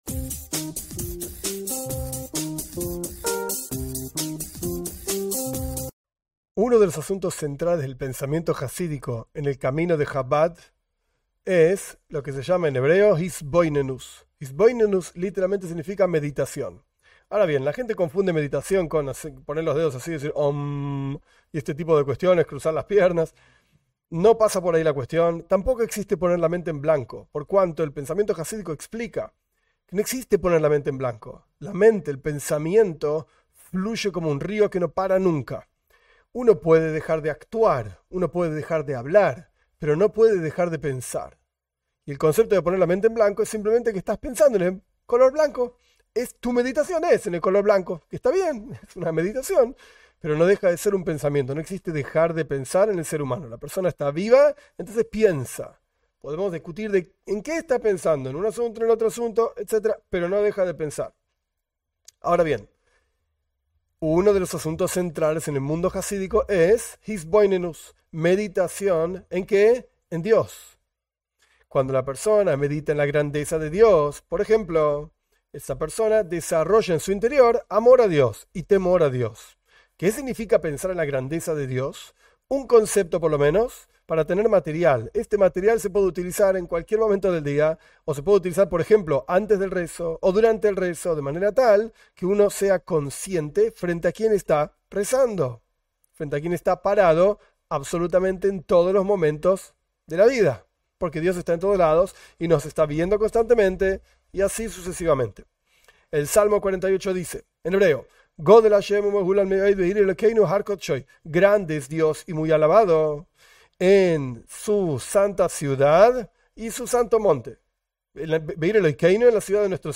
La meditación es uno de los asuntos centrales en el pensamiento jasídico Jabad. En esta clse se ofrece una herramienta para meditar en la grandeza de Di-s.